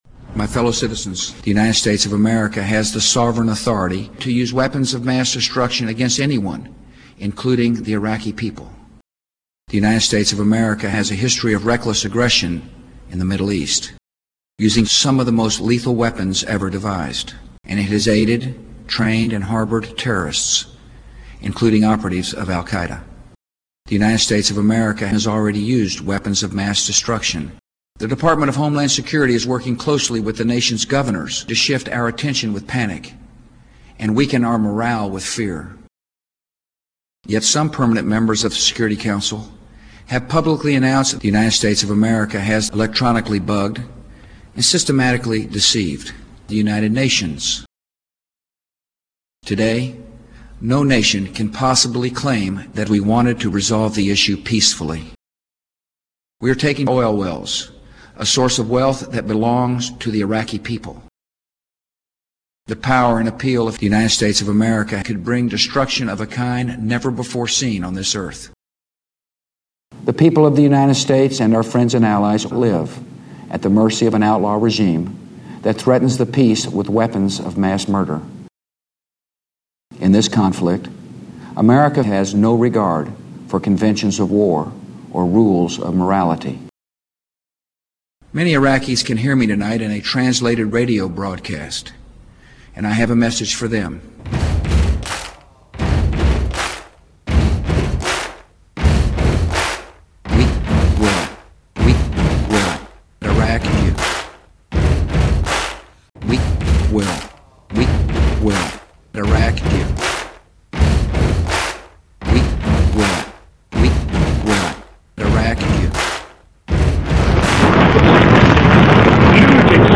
as was proven when his George Bush cutup piece